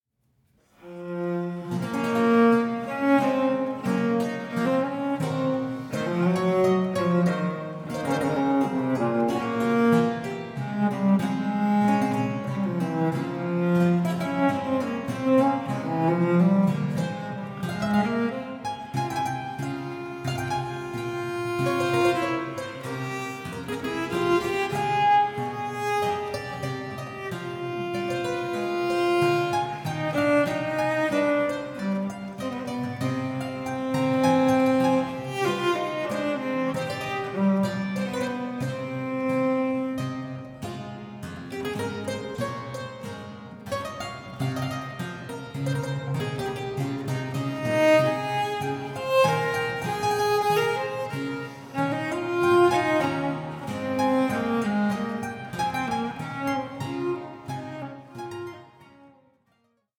five-string cello piccolo
clavichord